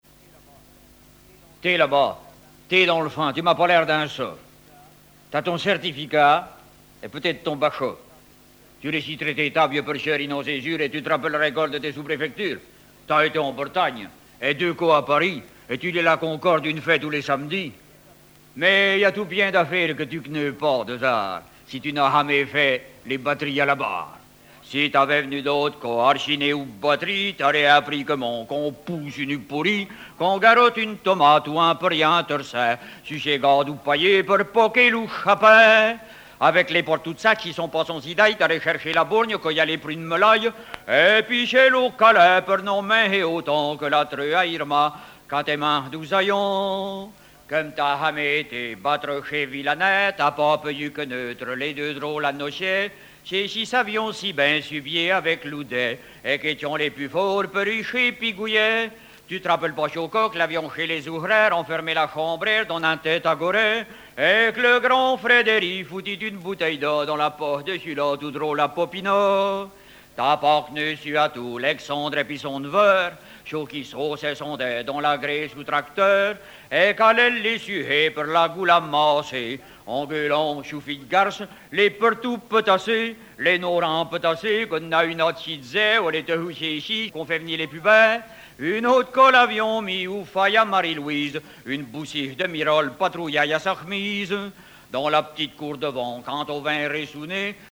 Langue Poitevin-Saintongeais
Genre sketch
Catégorie Récit